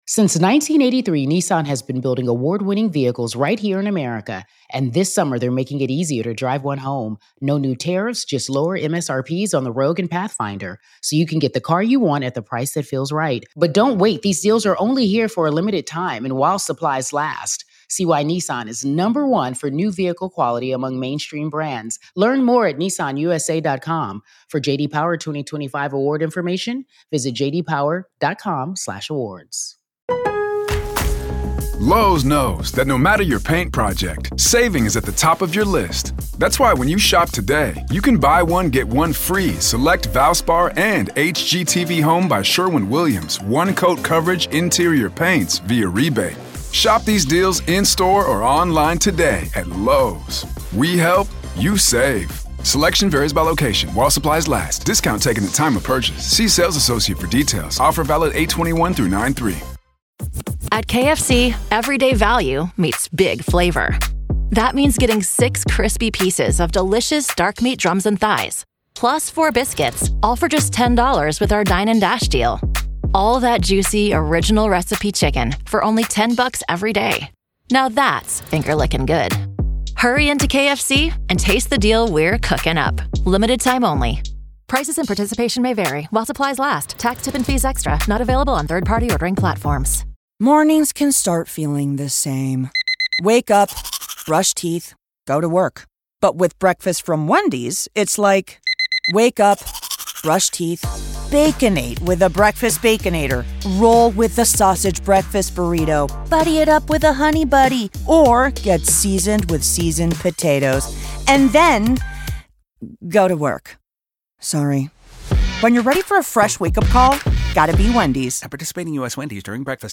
Listen As Manhattan DA Announces First Degree Murder Charges Against United Healthcare CEO Shooter